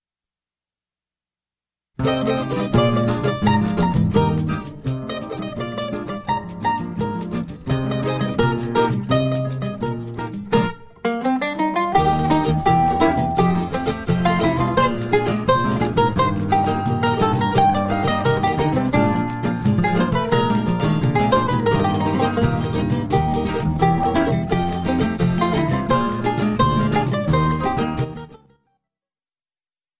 Choro ensemble